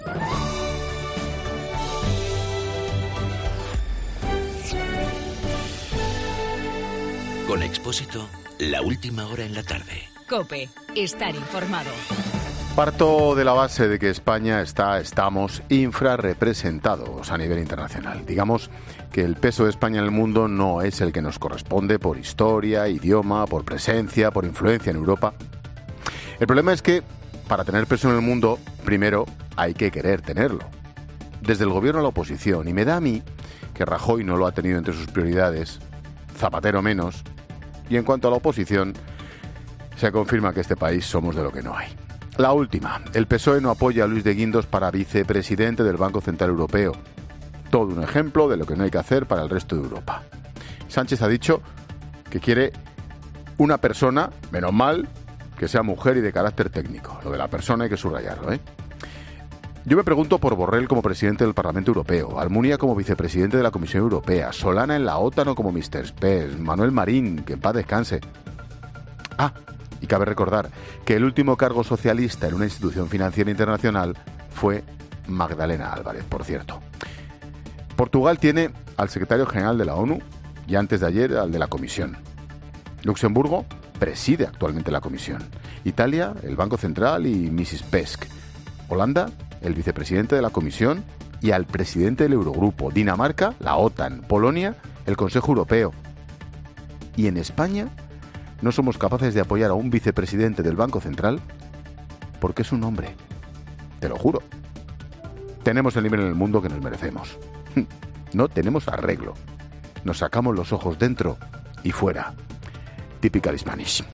AUDIO: El comentario de Ángel Expósito sobre el "no" apoyo a Luis de Guindos para la vicepresidencia del BCE.